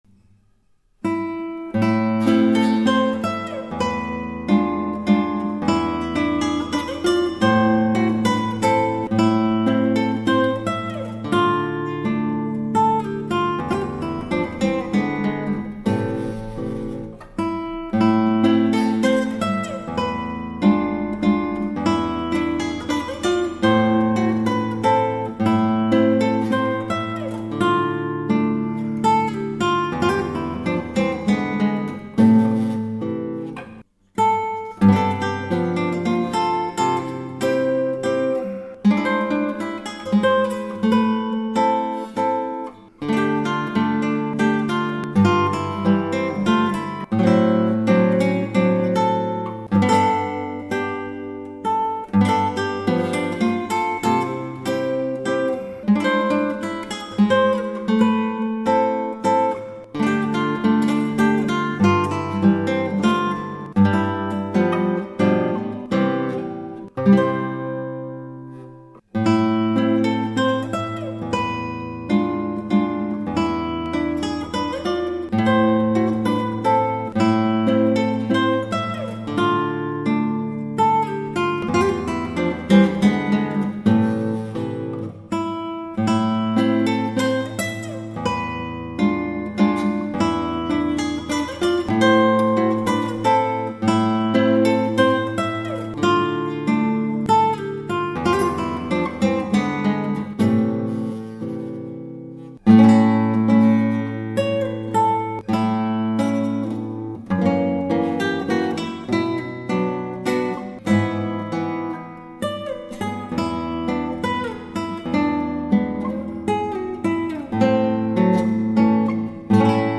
ギターはアルカンヘルで